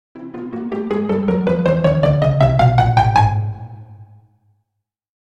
Funny Pizzicato Movement Sound Effect
Description: Funny pizzicato movement sound effect. Gradually increasing pizzicato sound in cartoon style, perfect for footsteps or movement. Adds playful, whimsical motion to animations, videos, or games. Fade-in effect creates a natural and fun audio progression.
Funny-pizzicato-movement-sound-effect.mp3